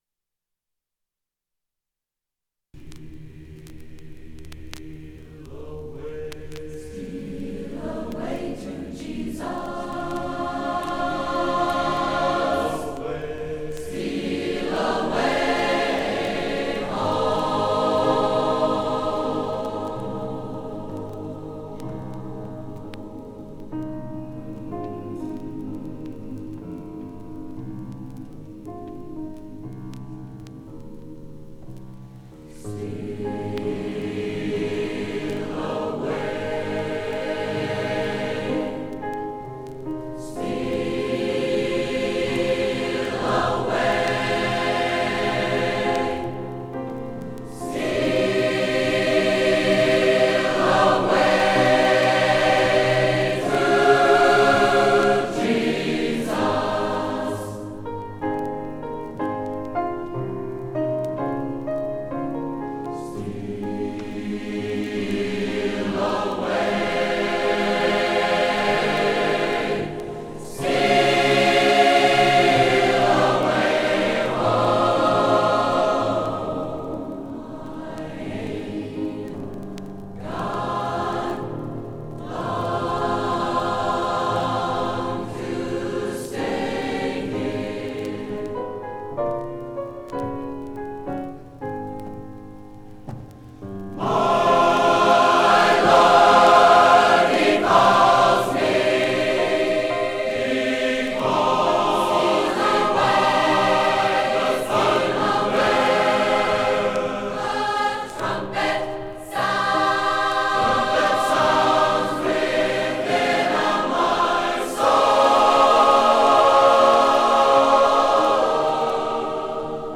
a3c1d282d1fcbc2a90c09f5aed74f19fa9ec0279.mp3 Title 1973 Music in May recording Description An audio recording of the 1973 Music in May performance at Pacific University.
It brings outstanding high school music students together on the university campus for several days of lessons and events, culminating in the final concert that this recording preserves.